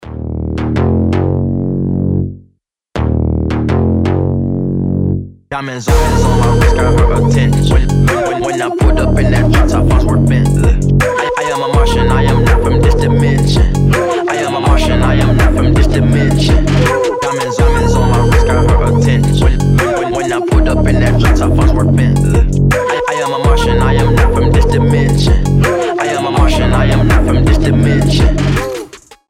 Trap
басы
качающие
Rap